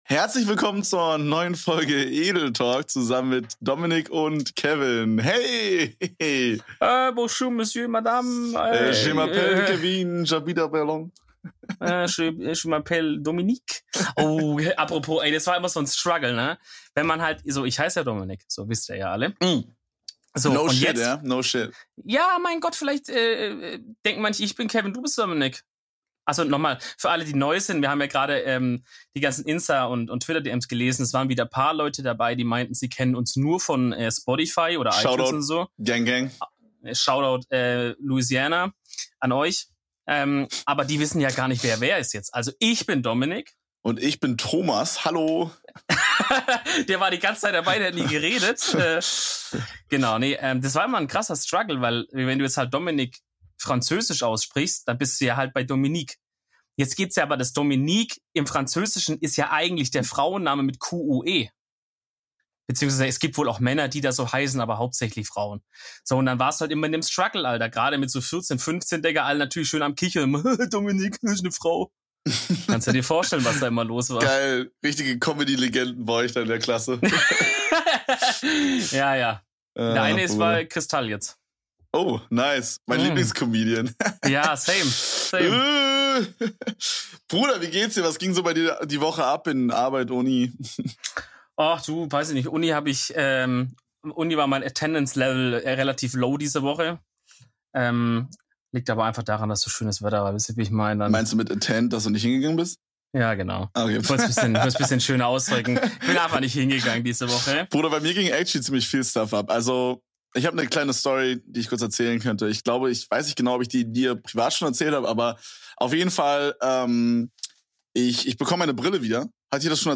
Poltergeist auf dem Dachboden? Dämon im Wandschrank? Wir lesen eure gruseligen Geschichten und Erlebnisse vor und fragen uns, ob es da noch mit rechten Dingen zugehen kann.